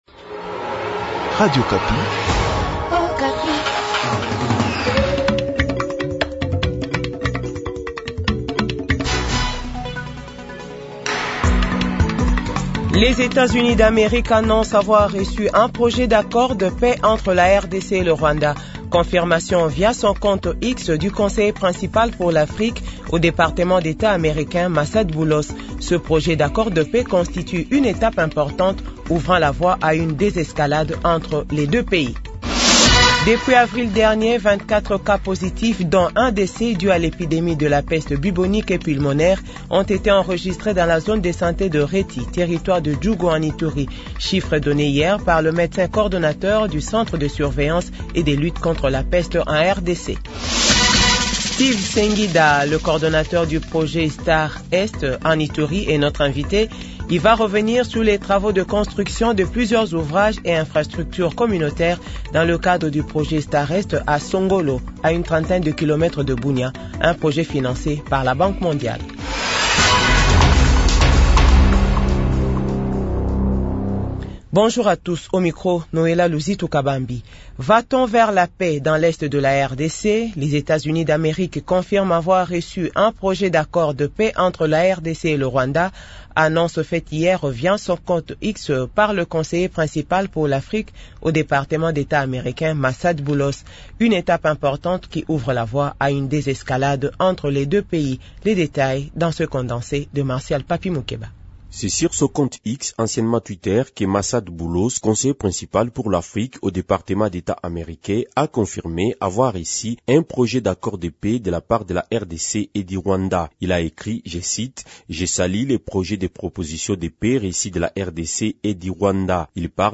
Journal 12h